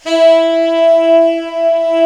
Index of /90_sSampleCDs/Giga Samples Collection/Sax/SAXIBAL LONG
TENOR SOFT.2.wav